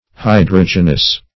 Search Result for " hydrogenous" : The Collaborative International Dictionary of English v.0.48: Hydrogenous \Hy*drog"e*nous\, a. Of or pertaining to hydrogen; containing hydrogen.
hydrogenous.mp3